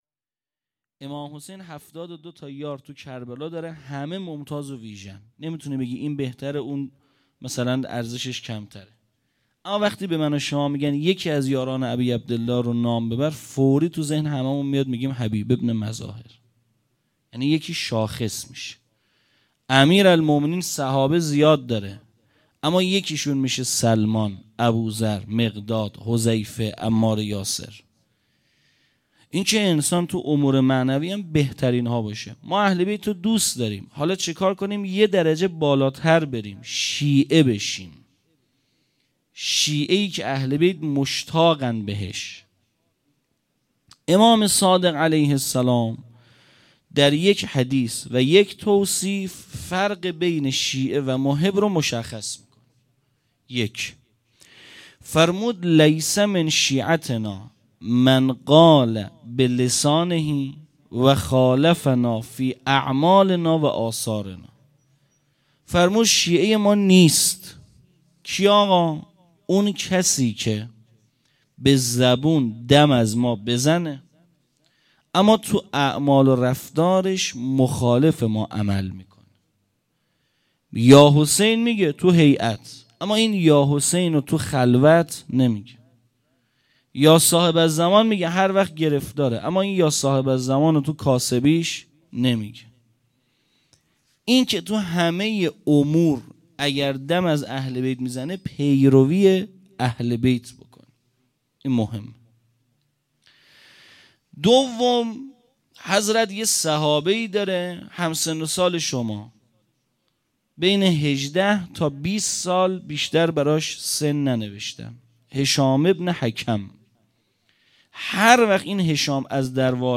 1 0 سخنرانی